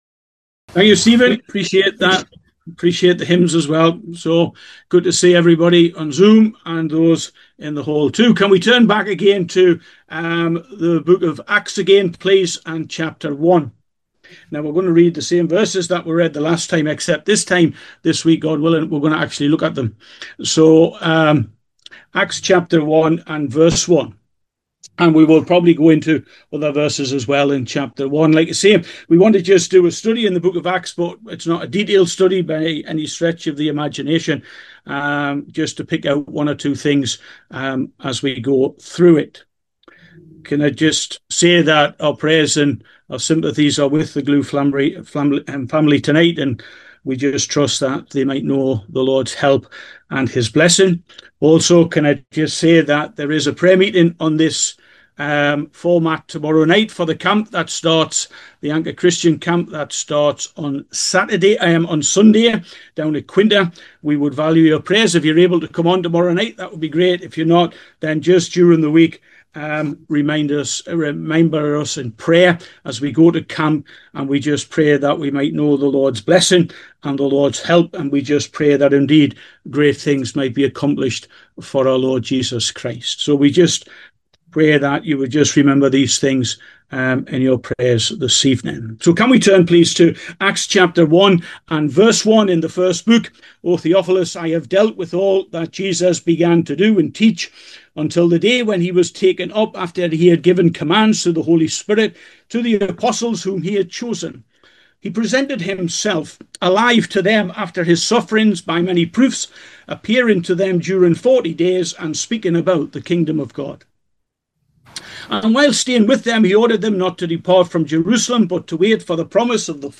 Bible Teaching Passage